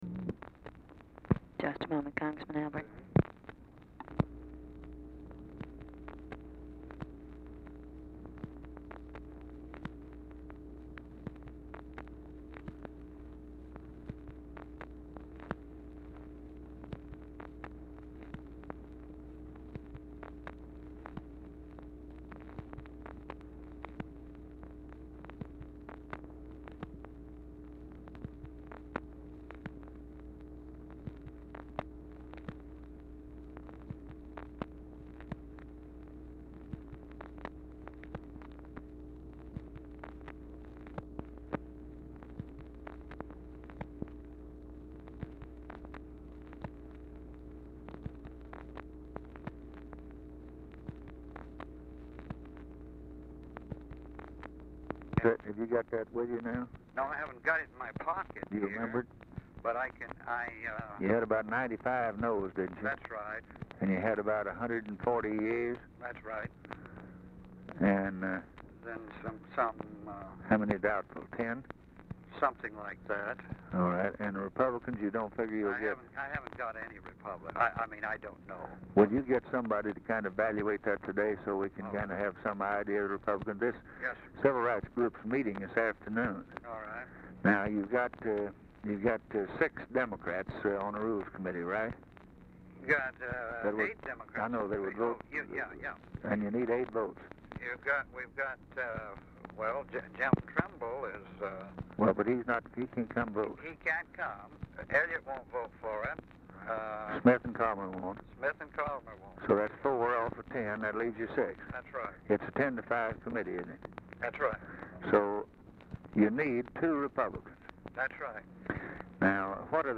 Telephone conversation
RECORDING STARTS AFTER CONVERSATION HAS BEGUN
Format Dictation belt